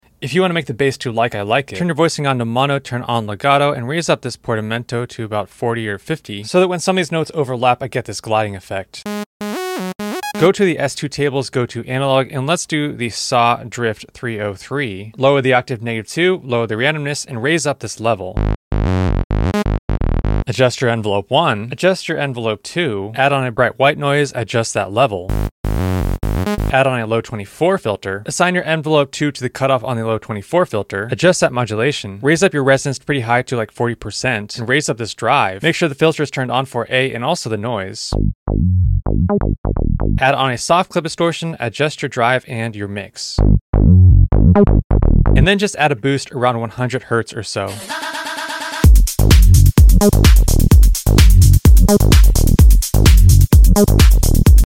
Tags: bass
tech house serum presets How To